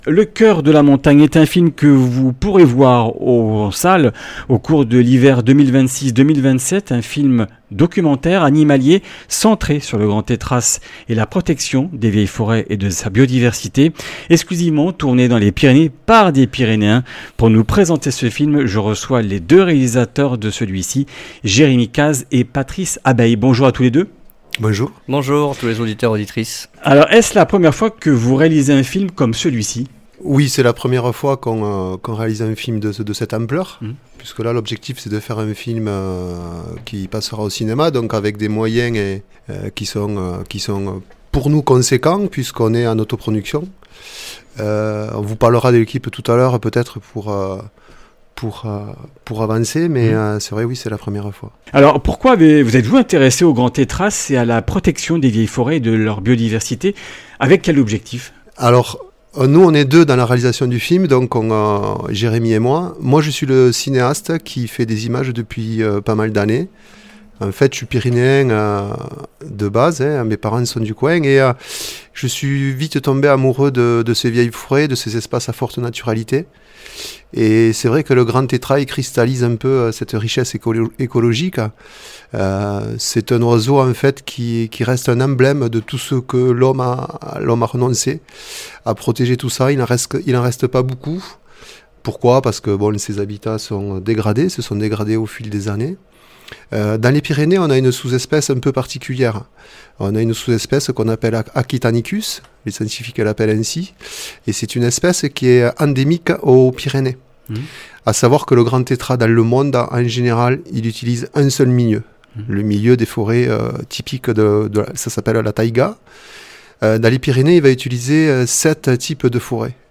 “Le cœur de la montagne” est un film que vous pourrez voir en salle au cours de l’hiver 2026-2027, un film documentaire animalier centré sur le grand tétras et la protection des vieilles forêts et de sa biodiversité, exclusivement tourné dans les Pyrénées, par des Pyrénéens. Interview